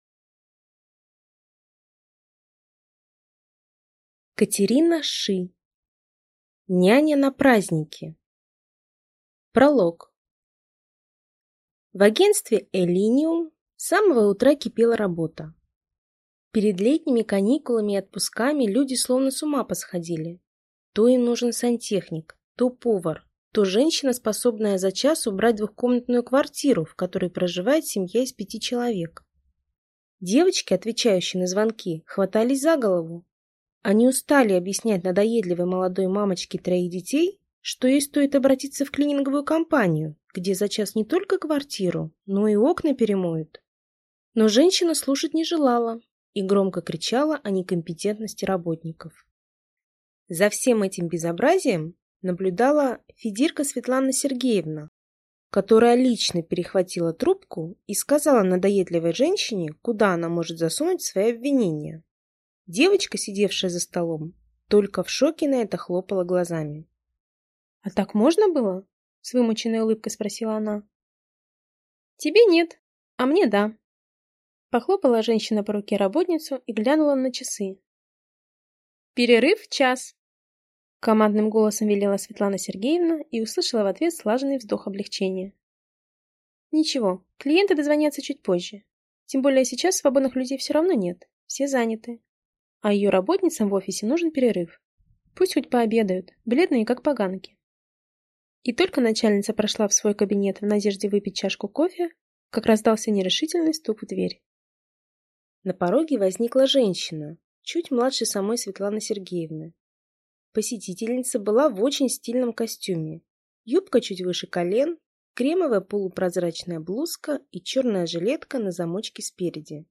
Aудиокнига Няня на праздники